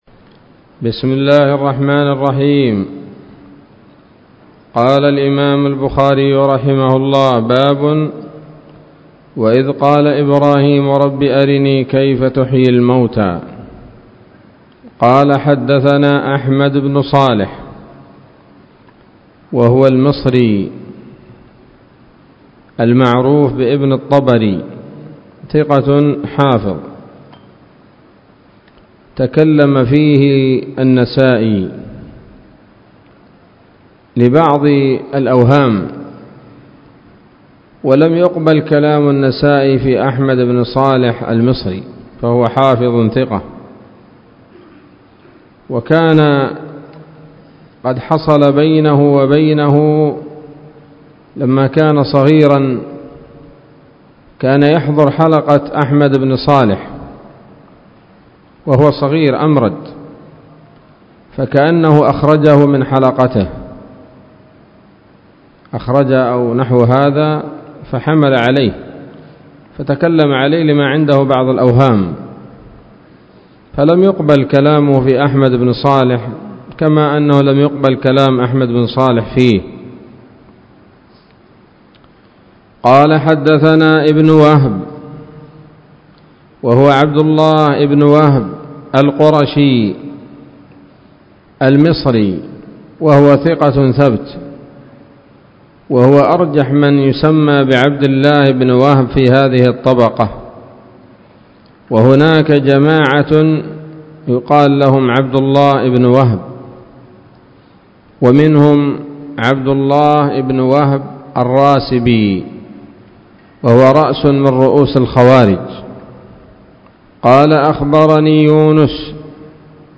الدرس التاسع والثلاثون من كتاب التفسير من صحيح الإمام البخاري